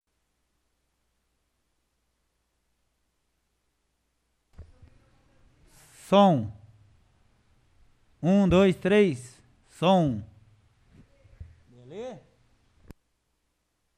7ª Sessão Ordinária — Câmara Municipal de Tamarana
7ª Sessão Ordinária